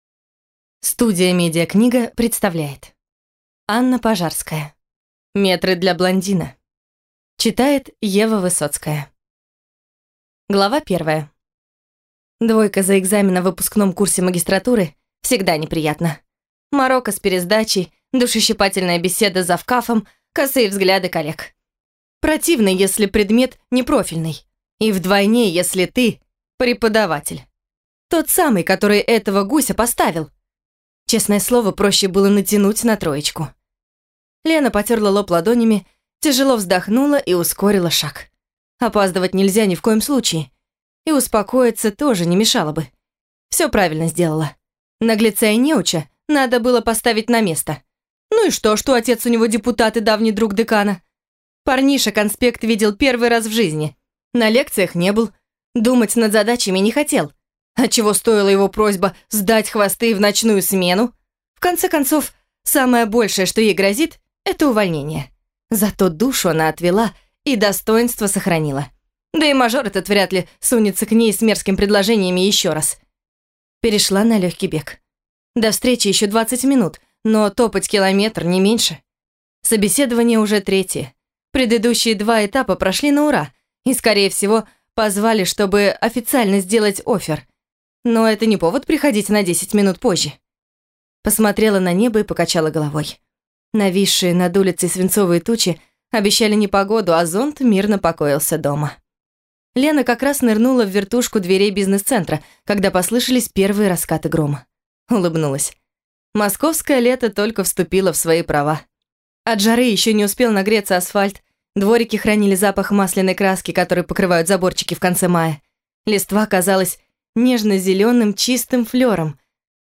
Аудиокнига Метры для блондина | Библиотека аудиокниг